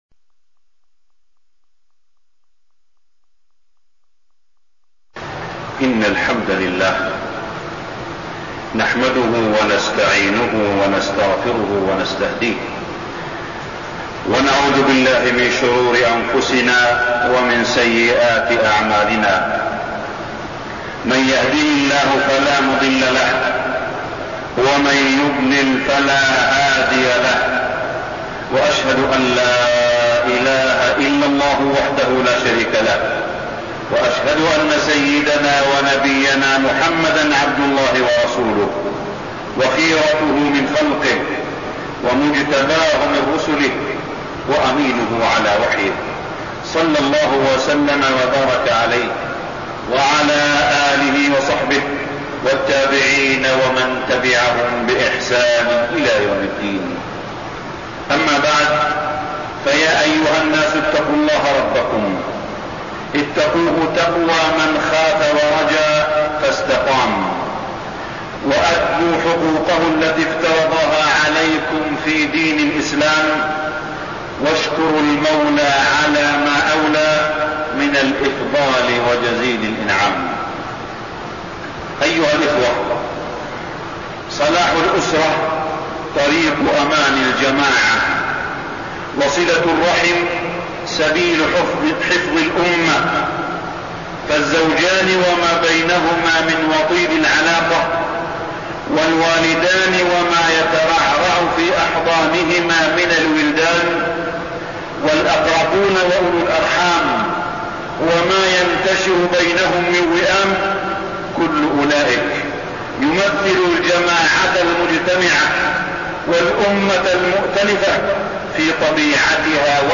تاريخ النشر ٢٢ ربيع الثاني ١٤١٤ هـ المكان: المسجد الحرام الشيخ: معالي الشيخ أ.د. صالح بن عبدالله بن حميد معالي الشيخ أ.د. صالح بن عبدالله بن حميد صلة الرحم والقربى